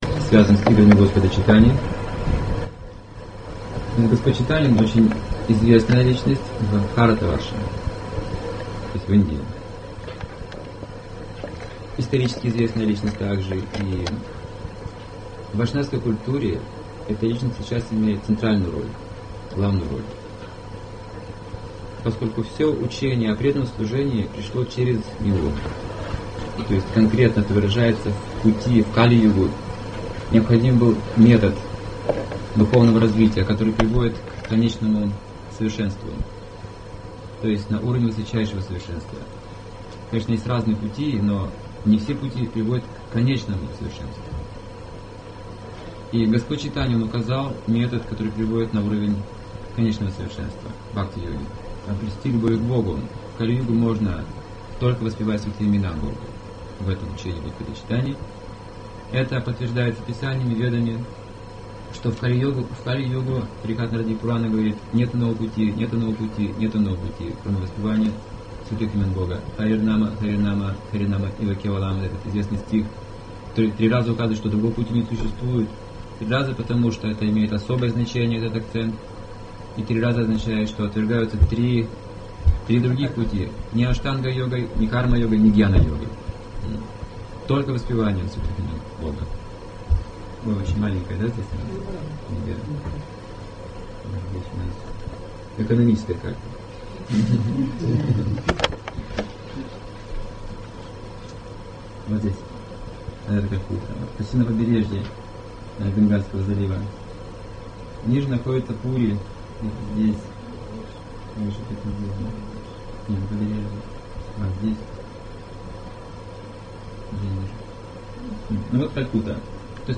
В лекции повествуется о месте явления Господа Чайтаньи - Шри Майпур Дхаме и храме ведического планетария. Лектор рассказывает о паломничестве (парикраме) по святым местам и самом большом празднике у вайшнавов Гаура-Пурниме.